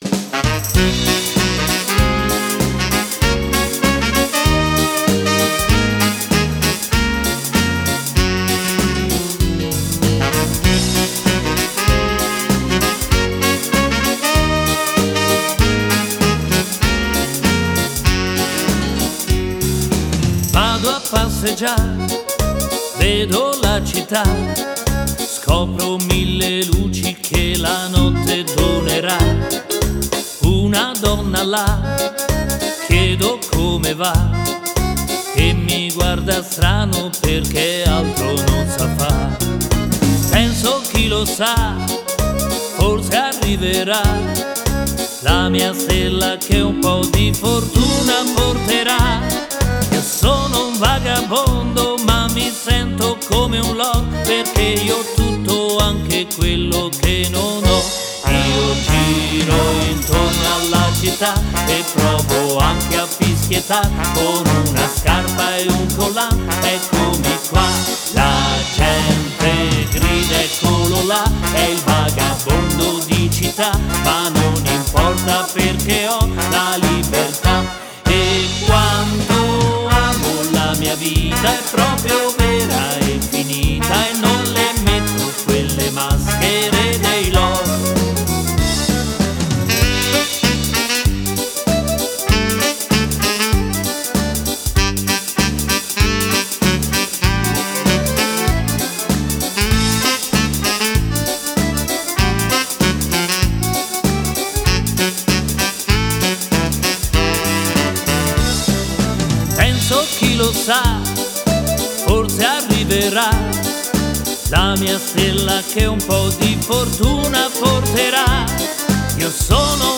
(versione uomo) Fox
(Fox cantato)